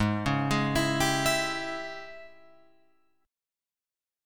G#M7sus4#5 Chord